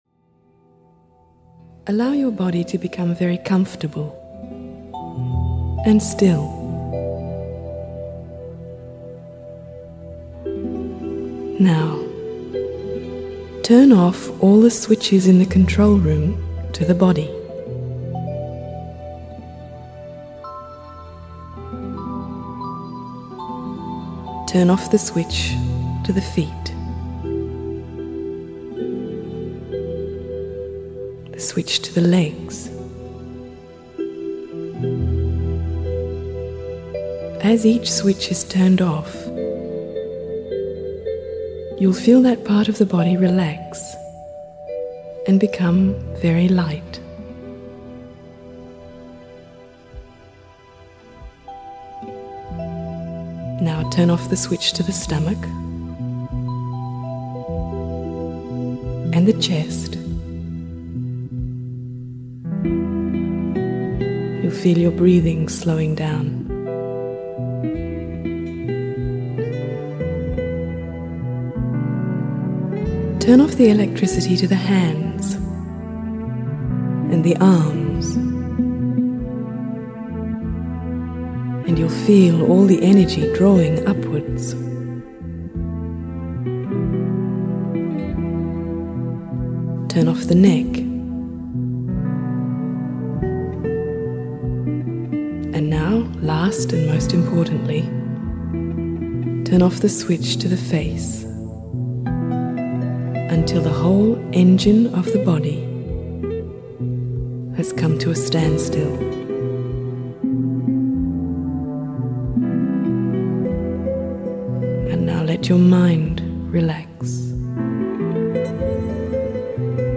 Guided meditation accompanied by calming and relaxing music.